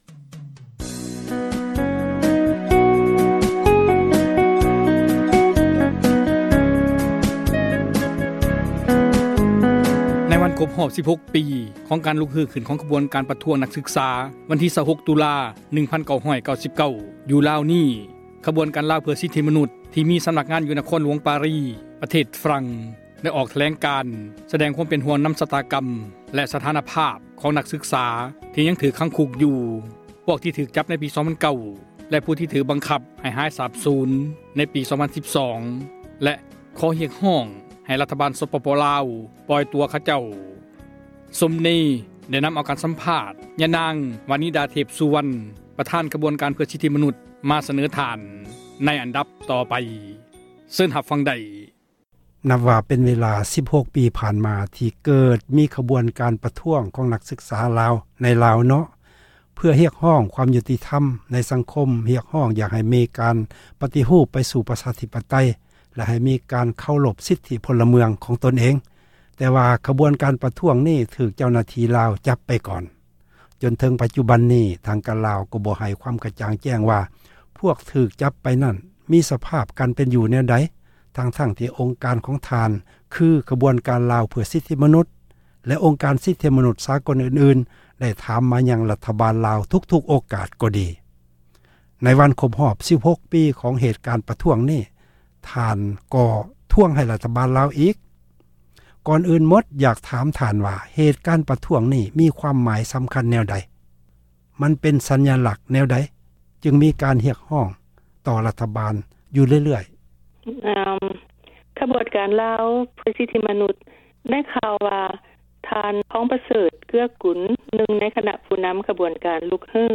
ການ ສັມພາດ